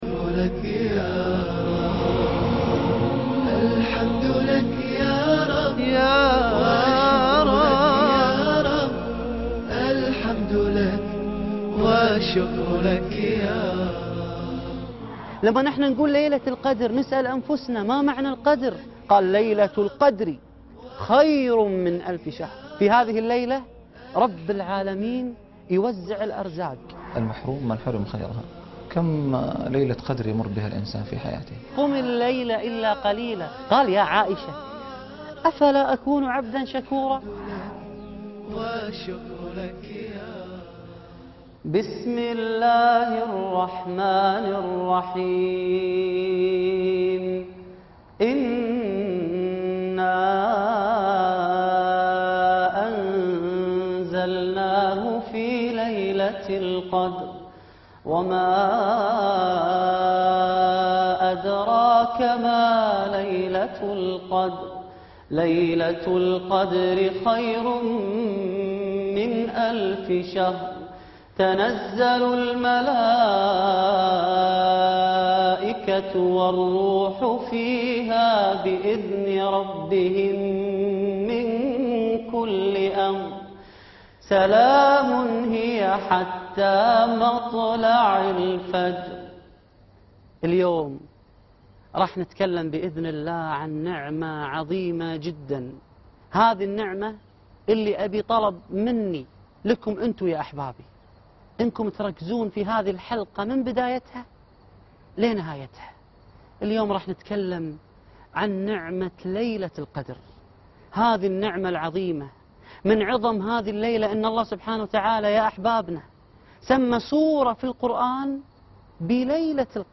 نعمة ليلة القدر (19/8/2011) عبدًا شكورا - القاريء فهد الكندري